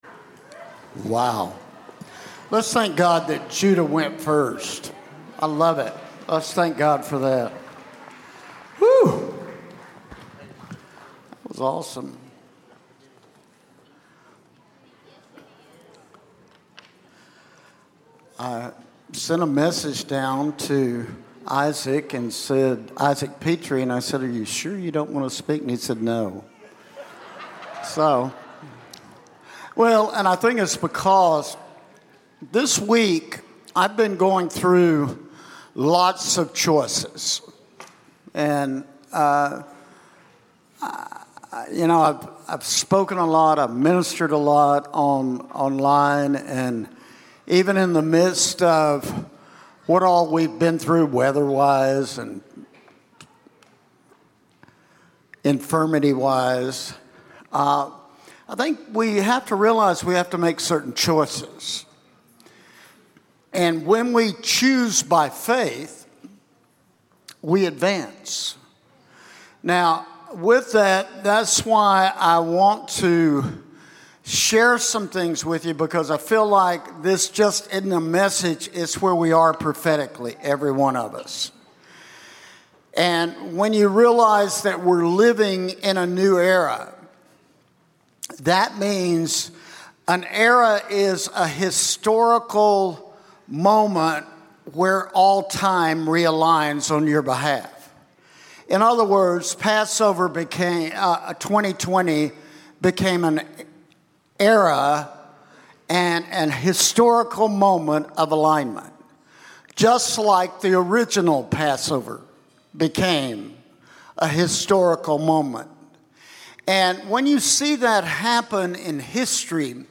Sunday Celebration Service